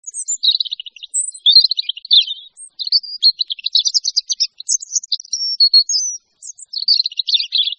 Le Traquet motteux